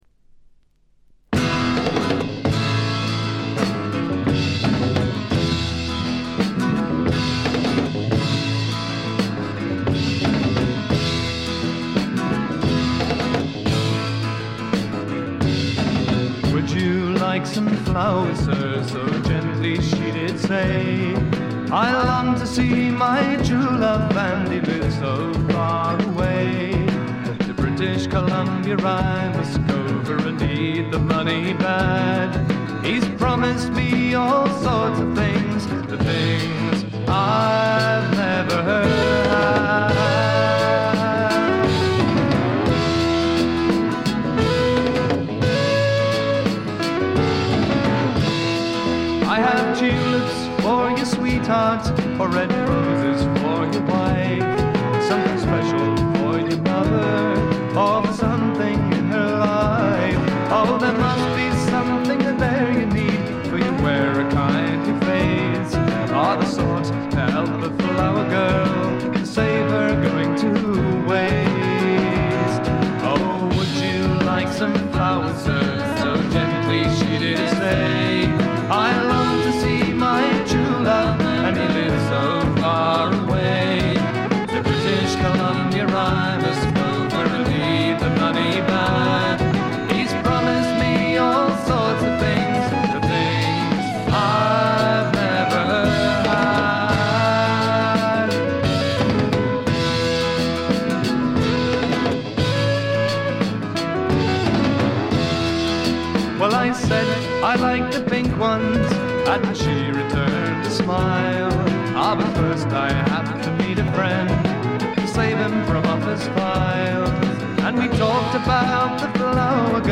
わずかなノイズ感のみ。
生きのよいフォークロックが最高ですよ。
試聴曲は現品からの取り込み音源です。